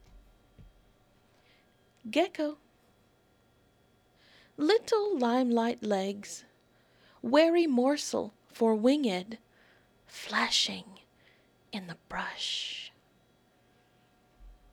CD Audio Book (Spoken Word/Music)